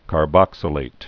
(kär-bŏksə-lāt)